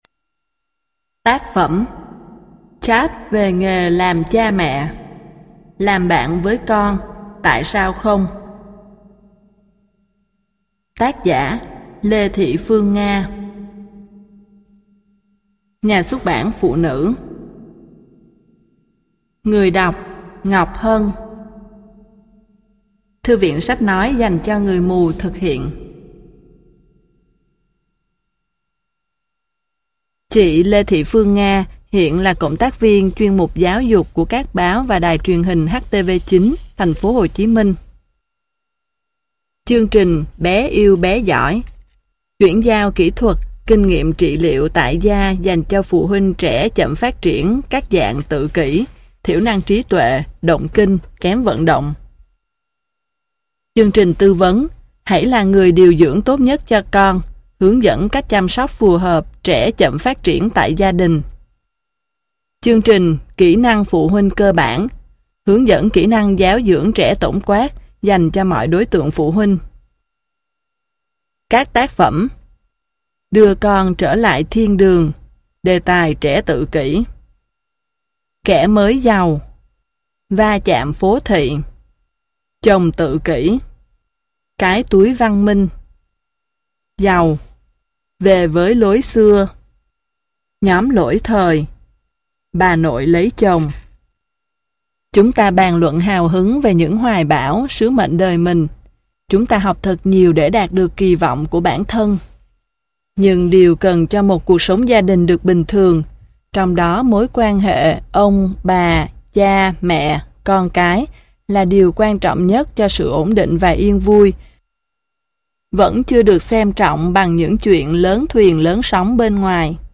Sách nói Chat Về Nghề Làm Cha Mẹ - Lê Thị Phương Nga - Sách Nói Online Hay